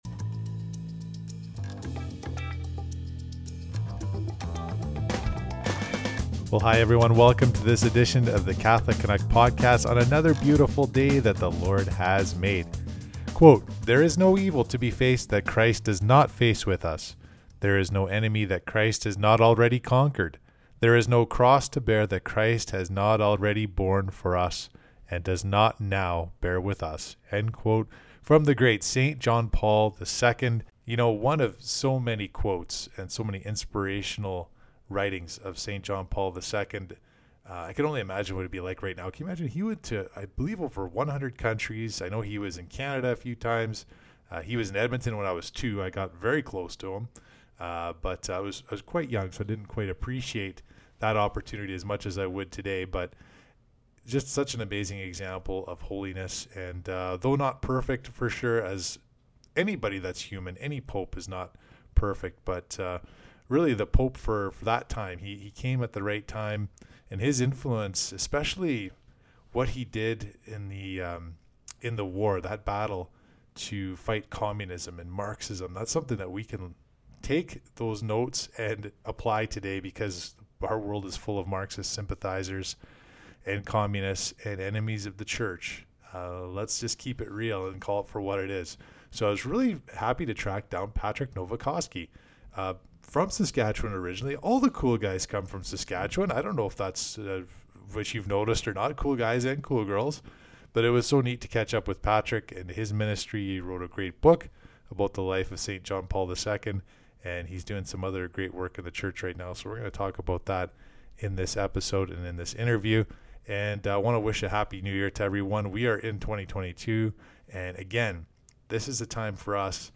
Jan 13, 2022 | Catholicism, faith, Interviews, Papal visit